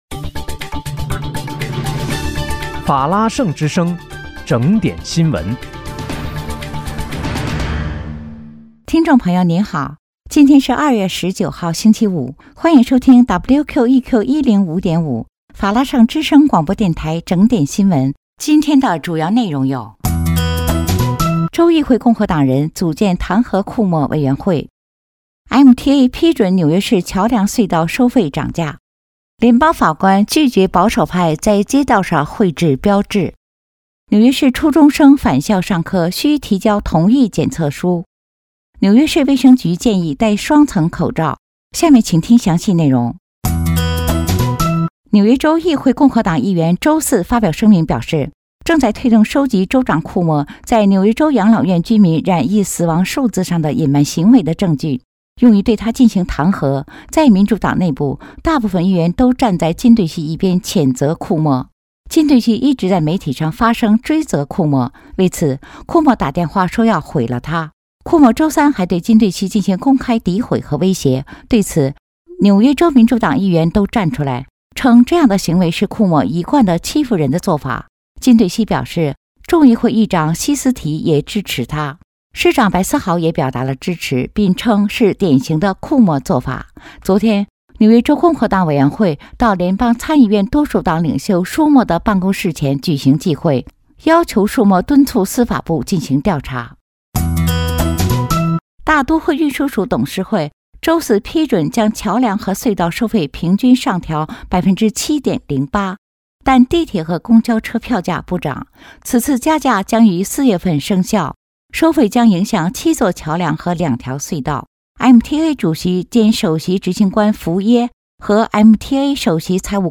2月19日（星期五）纽约整点新闻